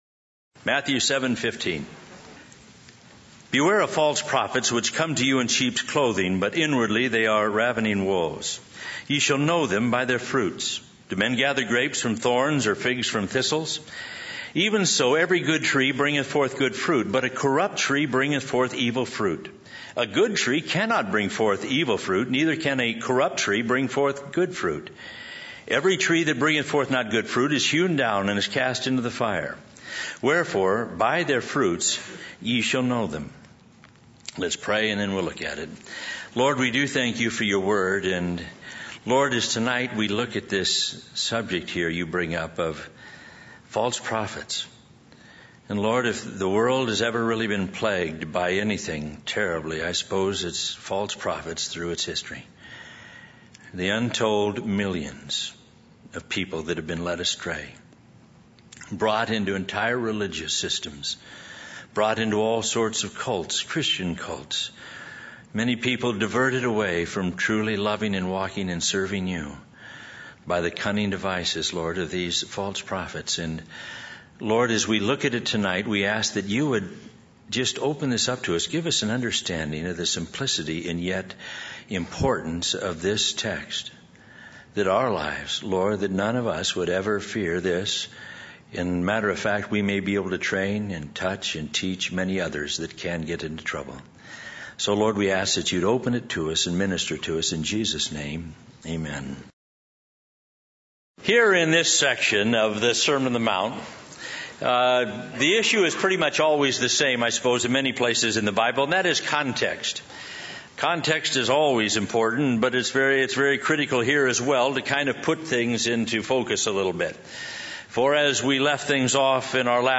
In this sermon, the speaker focuses on the importance of living according to the teachings of the Sermon on the Mount. He emphasizes that many of the disasters in life stem from not following these teachings and not trusting in God. The sermon covers various aspects of the Christian life, such as loving God and others, being merciful and pure in heart, and being a peacemaker.